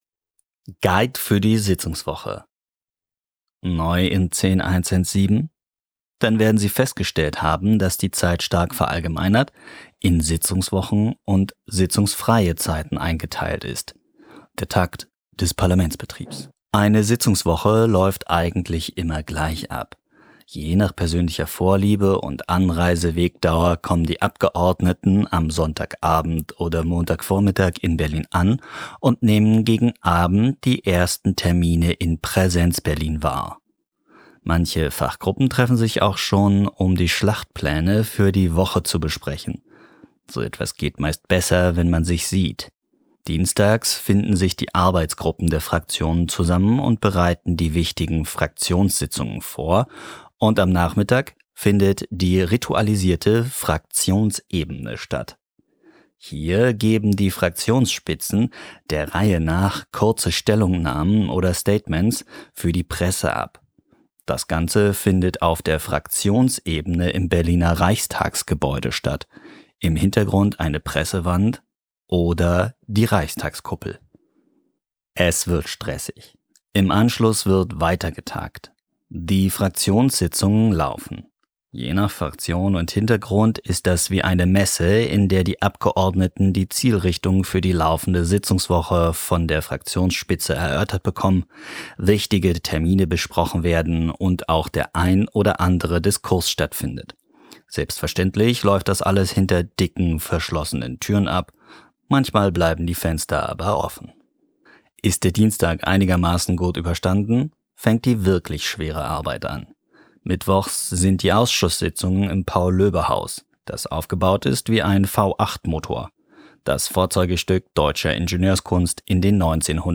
Lesungen und Hörbuch aus Berlin